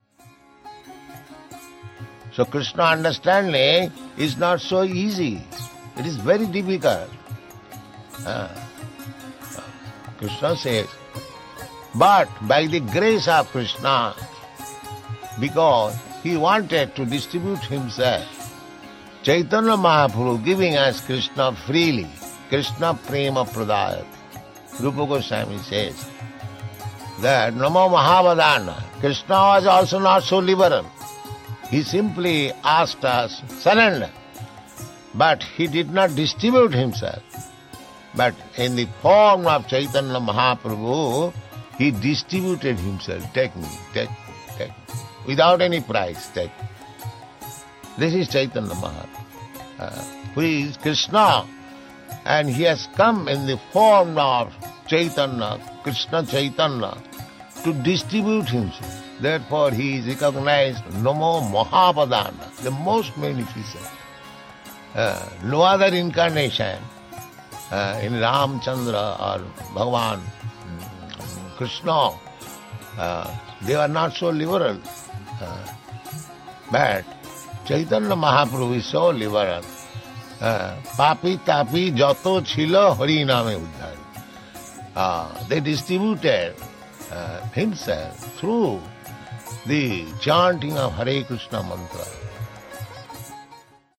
(721031 - Lecture SB 01.02.20 - Vrndavana)